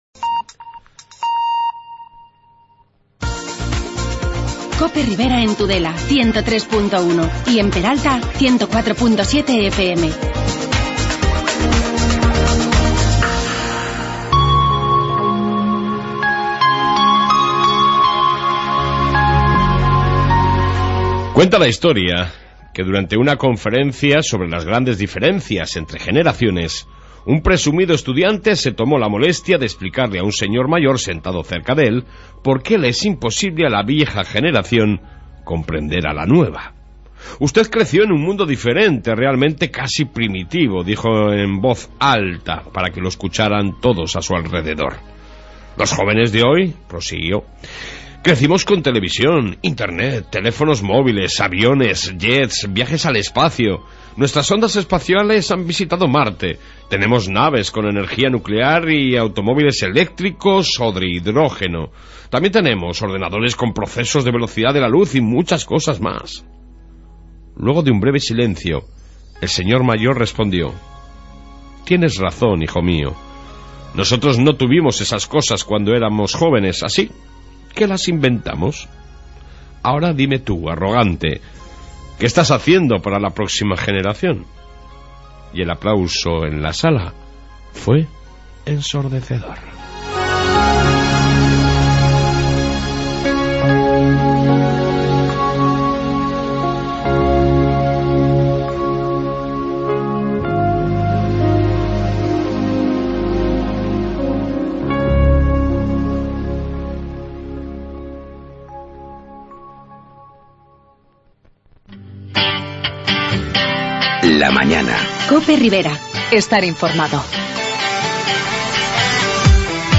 AUDIO: Entrevista con el concejal y teniente de alcalde de Tudela Joaquim Torrents